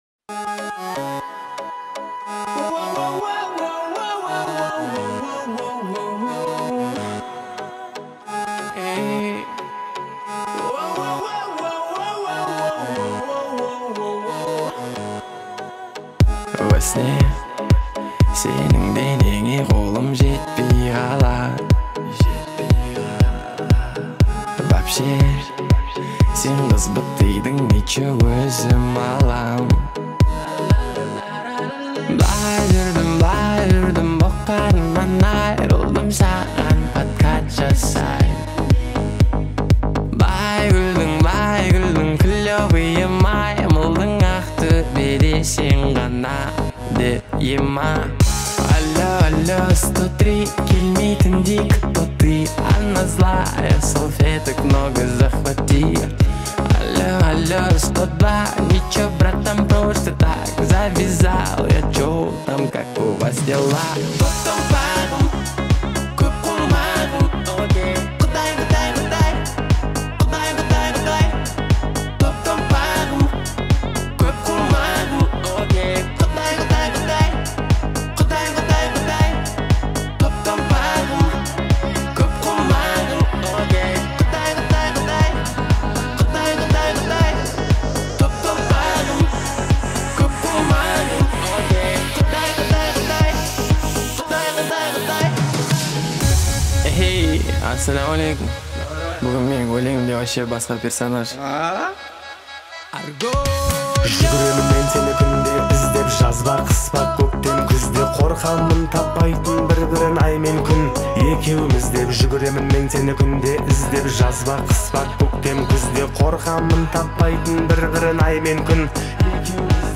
современного хип-хопа